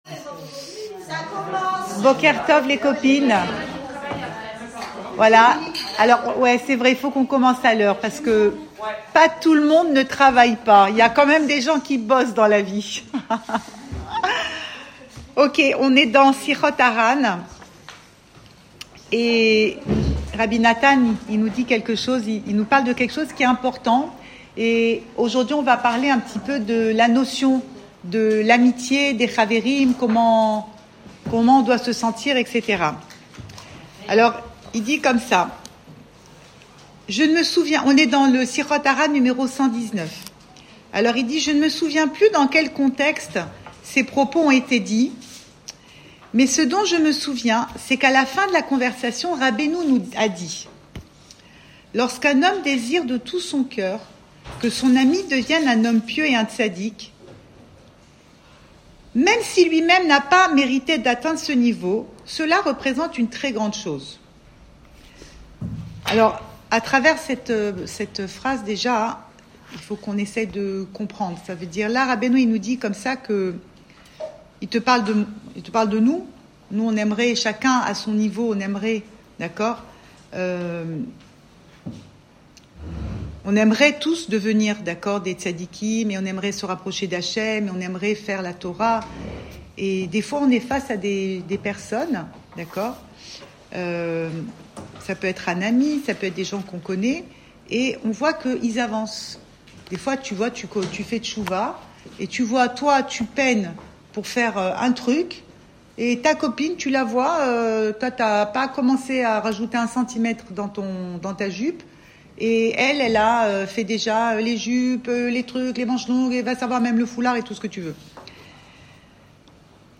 Cours audio Emouna Le coin des femmes Le fil de l'info Pensée Breslev - 5 mars 2025 6 mars 2025 Ton amie & toi. Enregistré à Tel Aviv